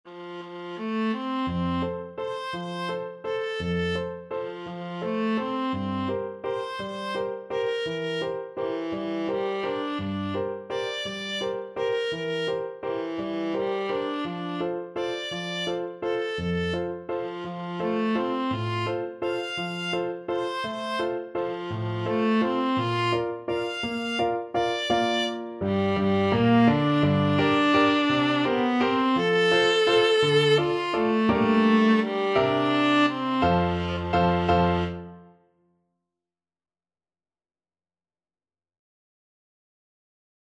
Classical Strauss II,Johann Blue Danube Waltz Viola version
ViolaPiano
3/4 (View more 3/4 Music)
=169 Steady one in a bar
F major (Sounding Pitch) (View more F major Music for Viola )
Classical (View more Classical Viola Music)